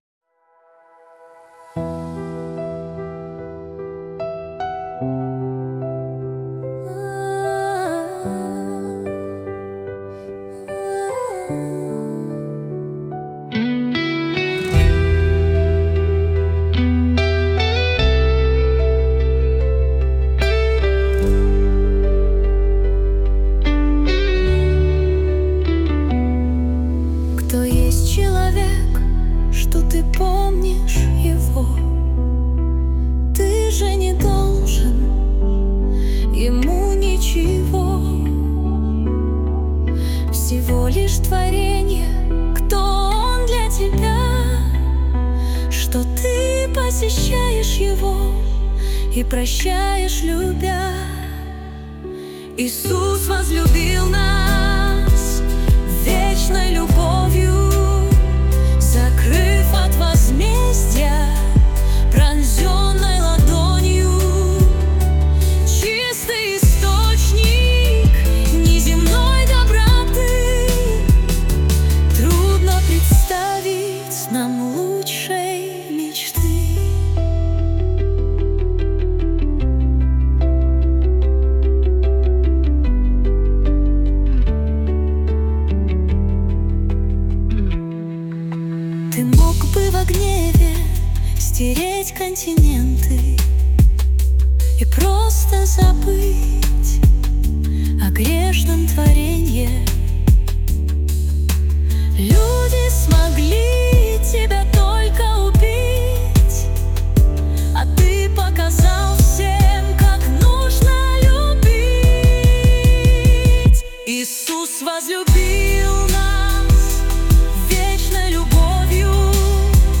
песня ai
215 просмотров 760 прослушиваний 82 скачивания BPM: 75